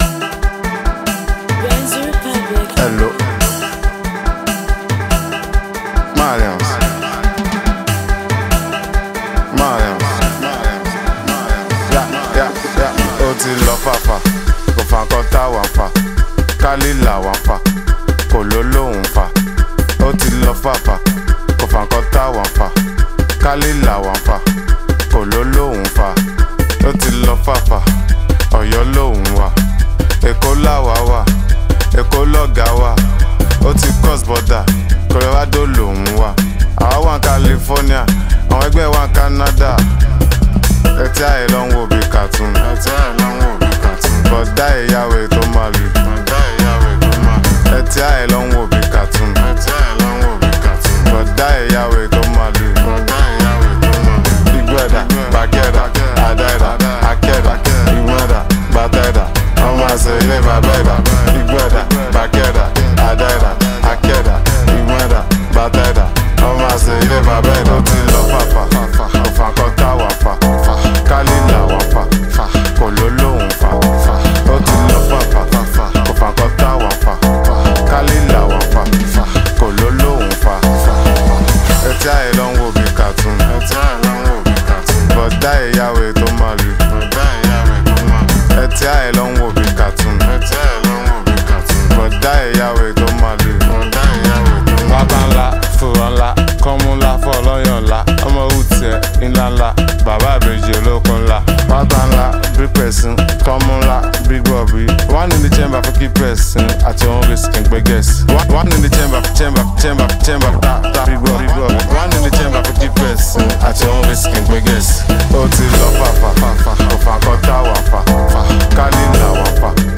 With its catchy rhythm and captivating flow